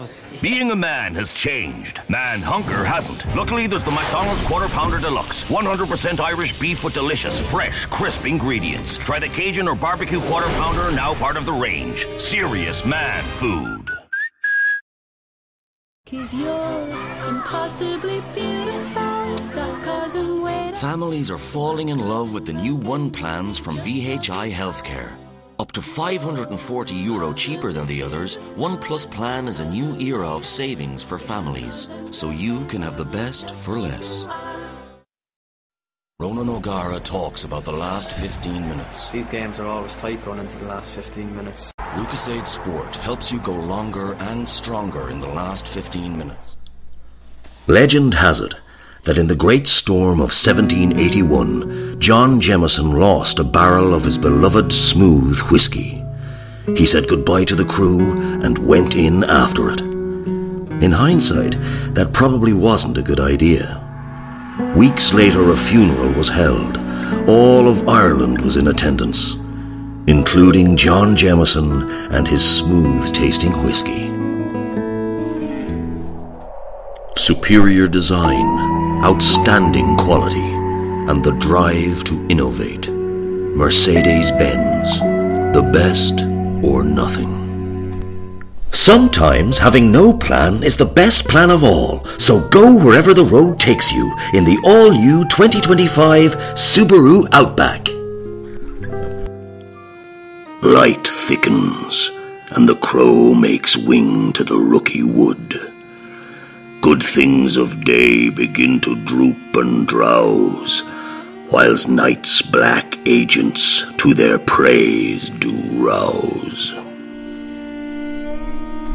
a rich, baritone voice with charm and assurance in equal measure.
soft Wexford accent
Alternative link 0:00 0:00 volume voice sampler Download 1MB Voice Notes